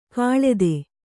♪ kāḷede